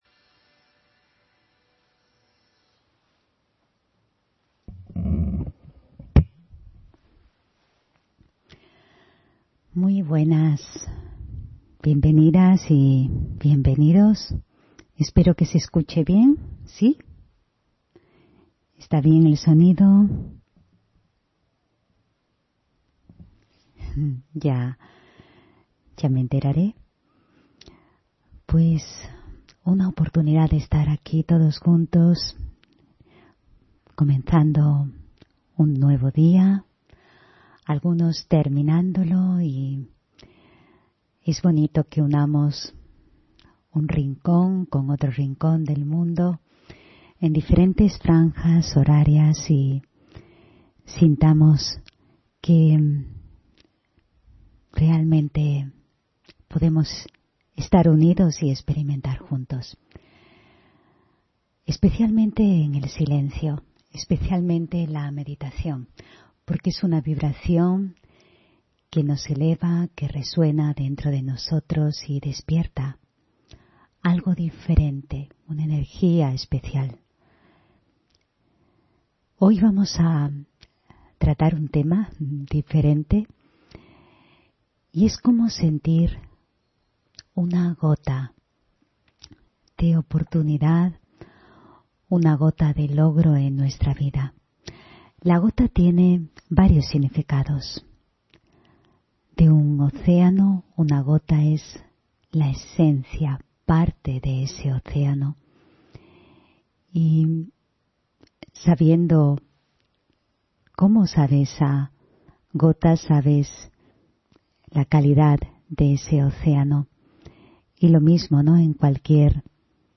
Meditación de la mañana: Una gota de existencia